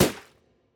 Interface And Item Sounds
Ballon (1).wav